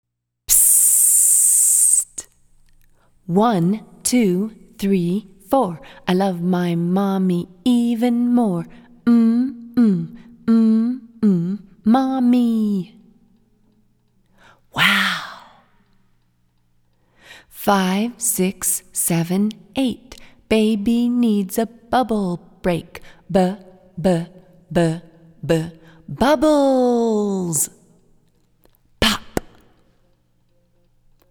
Psssst! Spoken Nursery Rhyme
Downloadable Spoken Story